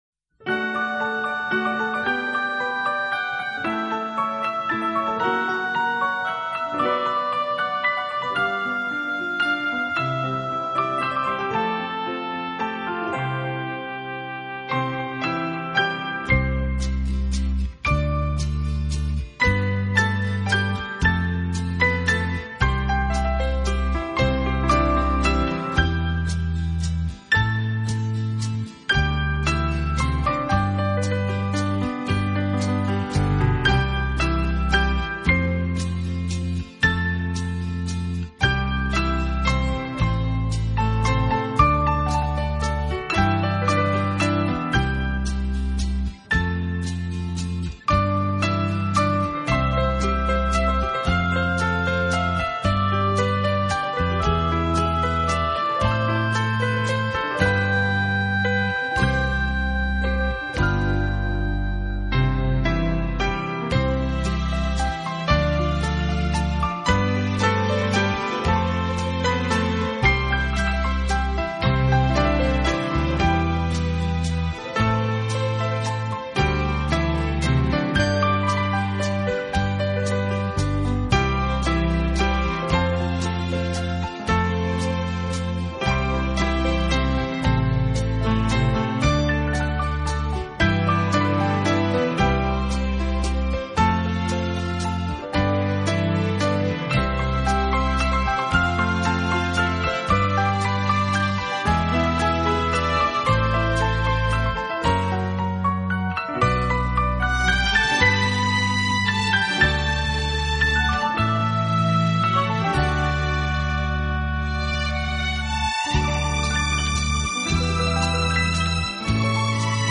钢琴演奏版，更能烘托出复古情怀，欧美钢琴大师深具质感的演奏功力，弹指